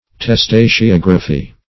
Search Result for " testaceography" : The Collaborative International Dictionary of English v.0.48: Testaceography \Tes*ta`ce*og"ra*phy\, n. [Testacea + -graphy: cf. F. testac['e]ographie.]
testaceography.mp3